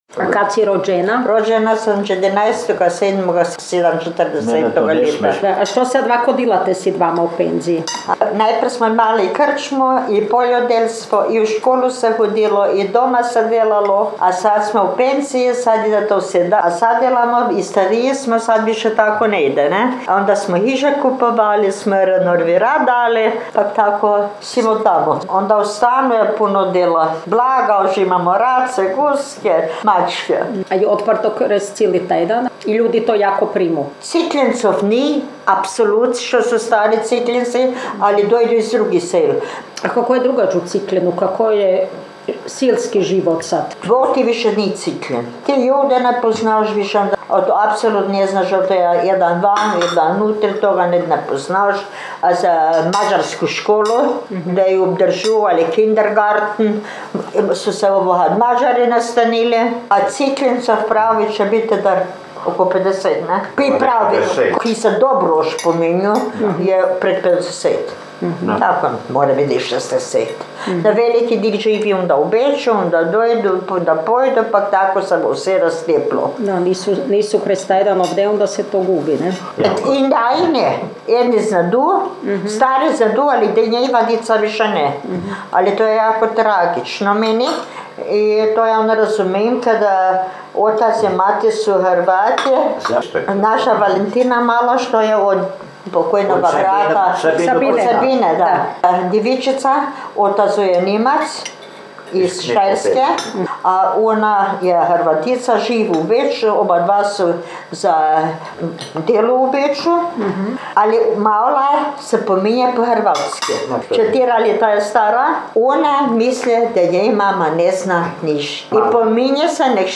jezik naš, jezik naš gh dijalekti
Hrvatski Cikljin – Govor